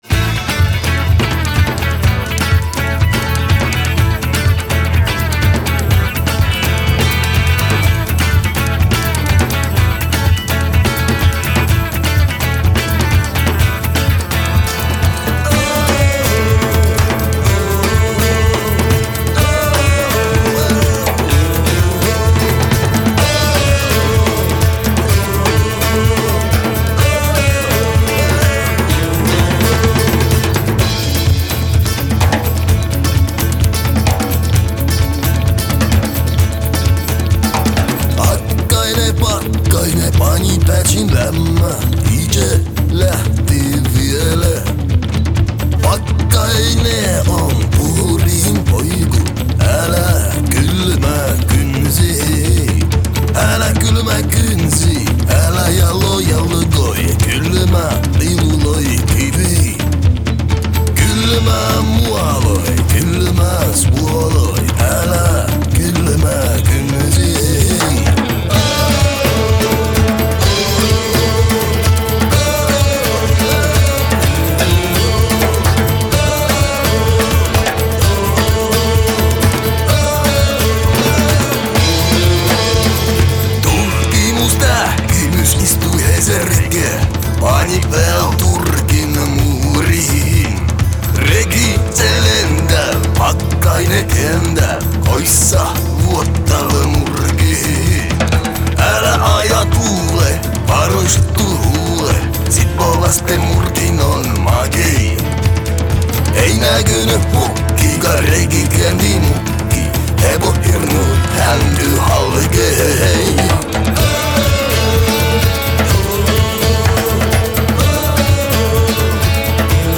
pajatandu, bass-gituaru, taganpajatandu
electrogituaru, gituaru, örineh
buzuki, huuligarmoniekku, garmoniekku, taganpajatandu
barabanat